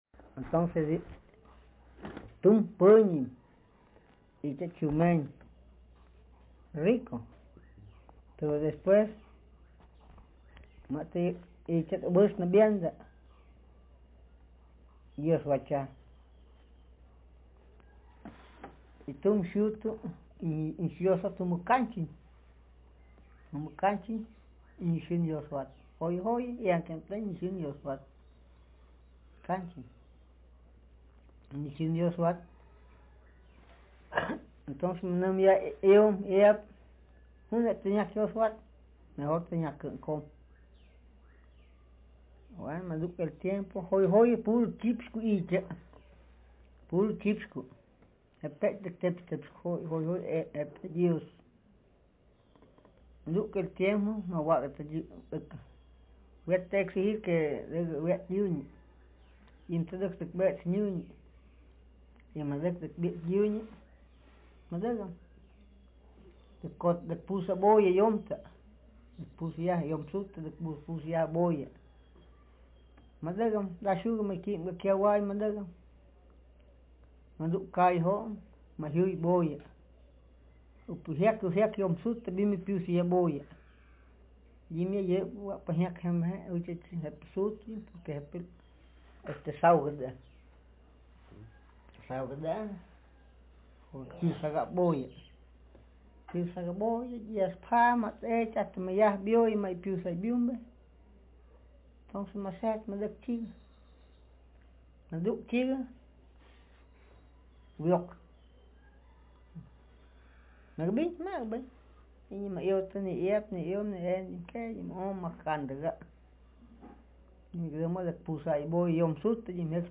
Speaker sex m Text genre traditional narrative